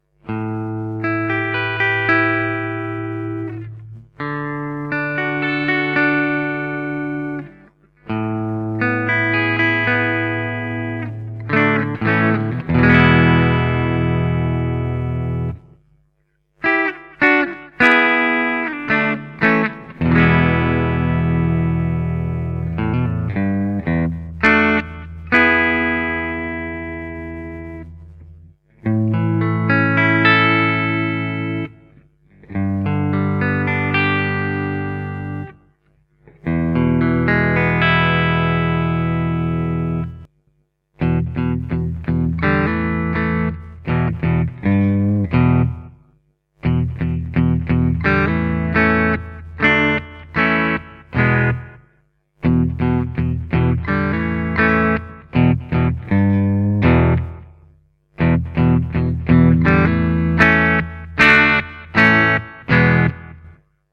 The Yellow Dog offers more bite and punch in the top end, together with plenty of warmth in the lower registers.